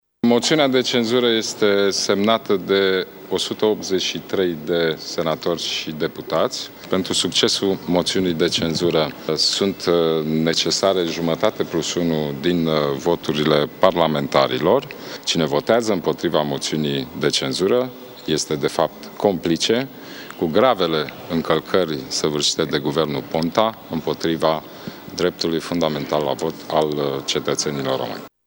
Moţiunea de cenzură este semnată de 183 de parlamentari, a precizat prim-vicepreședintele PNL, Ludovic Orban: